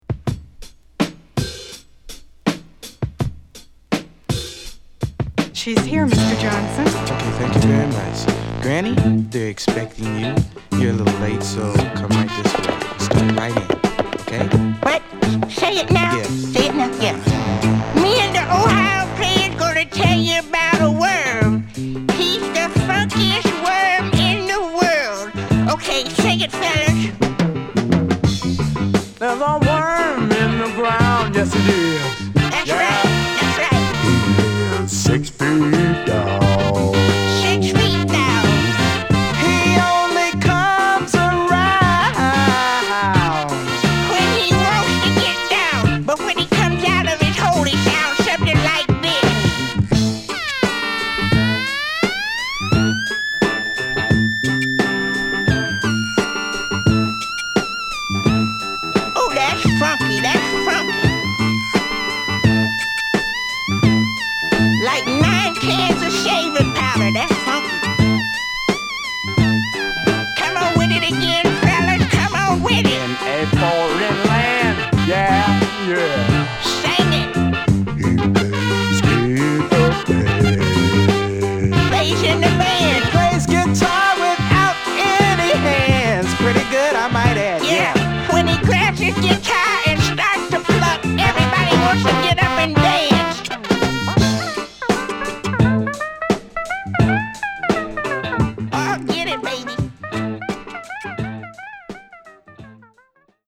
頭のドラムブレイクから虫が地を這うようなウネルシンセに飛ばされます！